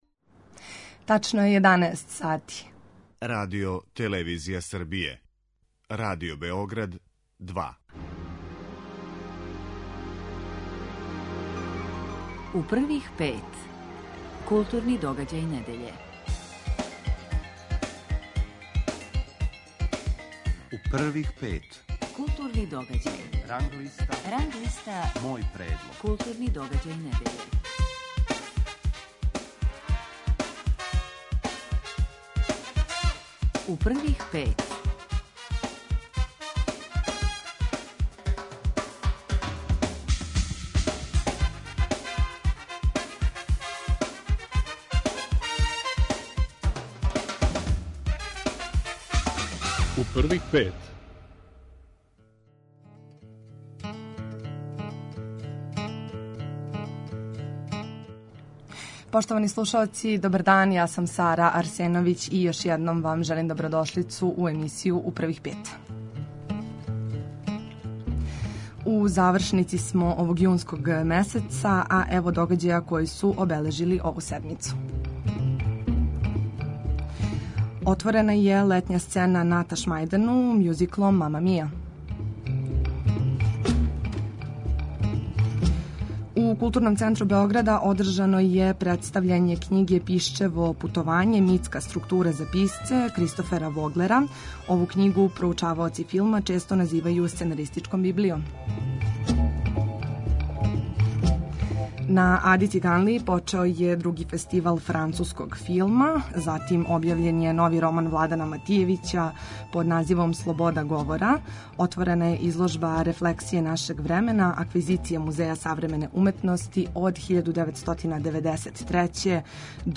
Са Игором Штиксом, књижевником, филозофом и универзитетским професором, разговарамо о његовом новом роману W.
Новинари и критичари Радио Београда 2 издвајају најбоље, најважније културне догађаје у свим уметностима у протеклих седам дана и коментаришу свој избор.